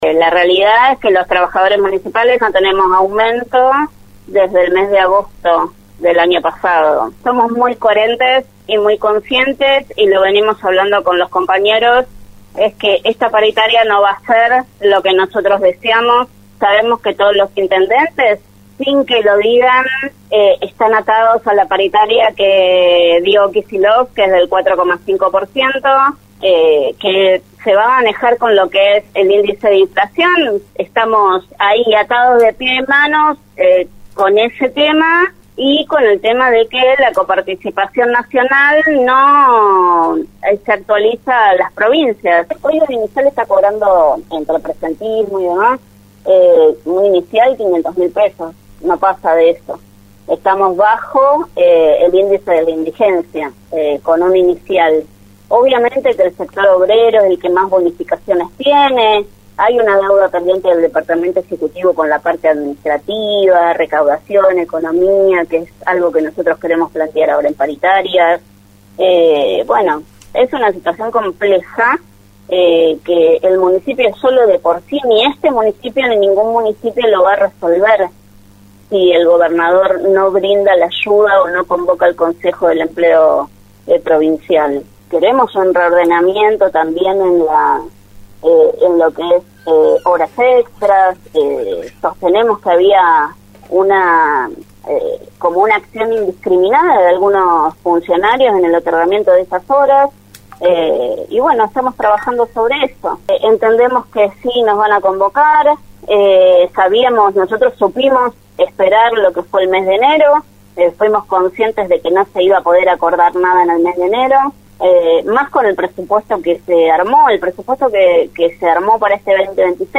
EN RADIO UNIVERSO